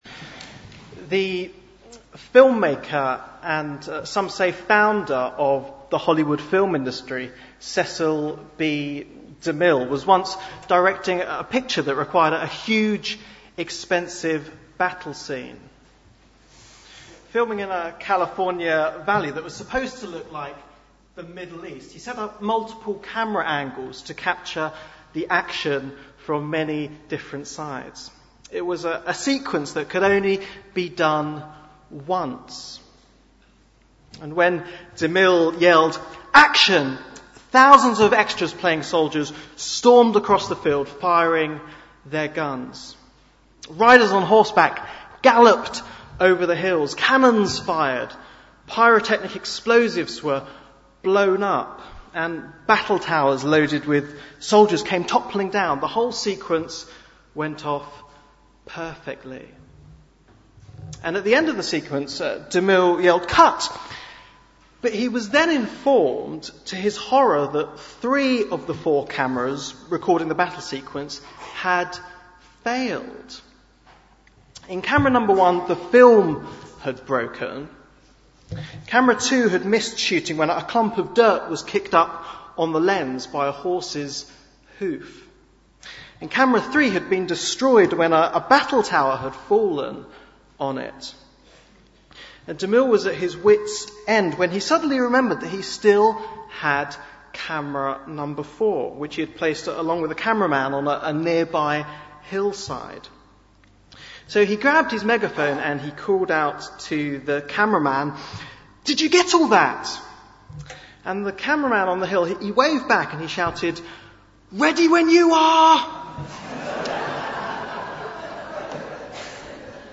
Bible Text: Matthew 24:36 – 25:13 | Preacher